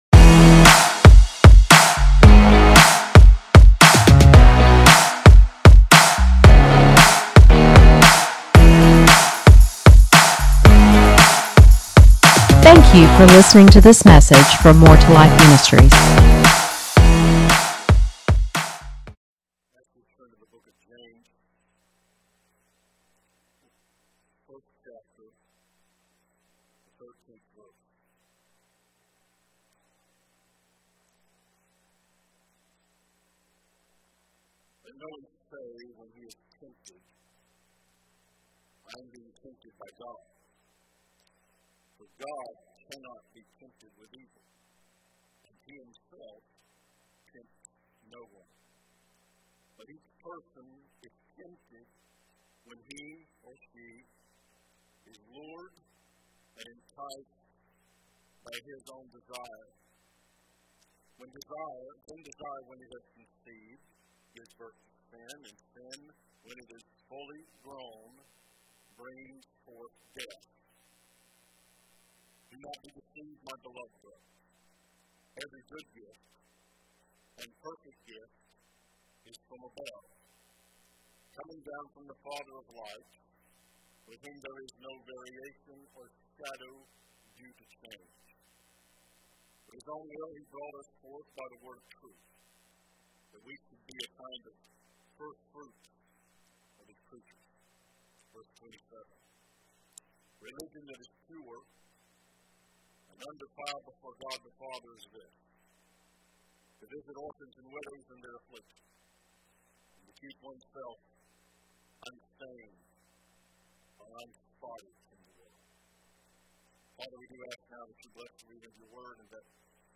Sermons | More 2 Life Ministries